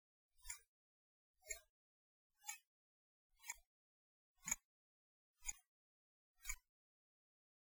Here you can hear the ticks of the B78 movement! Each click occurs when the second's hand is moving; the double-click is the minute's hand moving as well (the minute's hand jumps every 30 seconds).
breitling_b78_movement.ogg